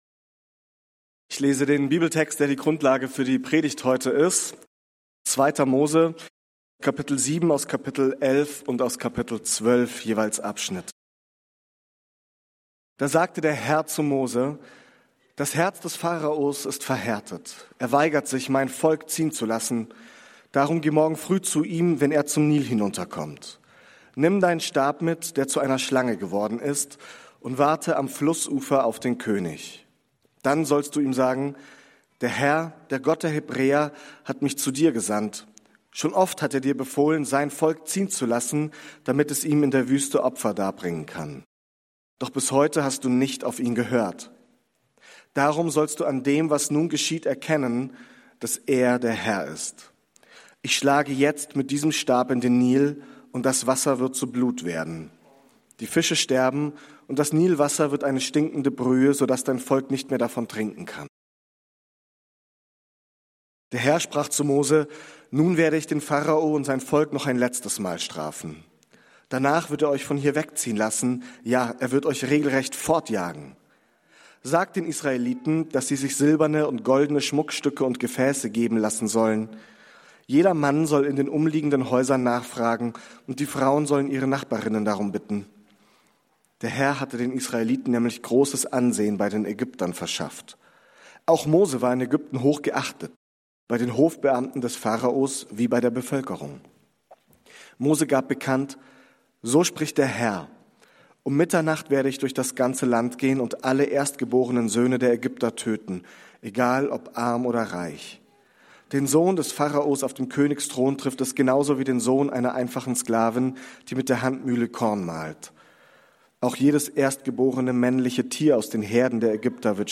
Wenn Gott unterbricht ~ Berlinprojekt Predigten Podcast